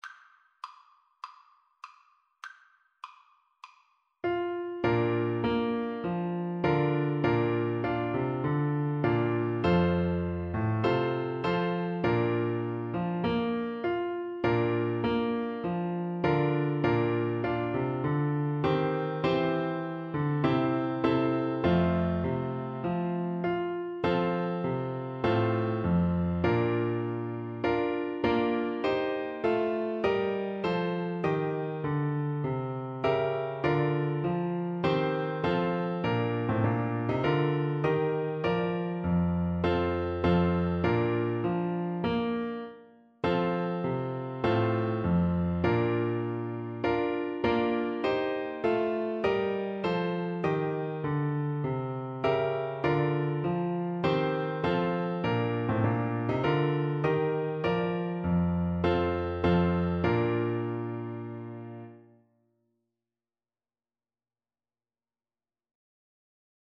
Moderato